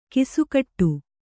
♪ kisukaṭṭu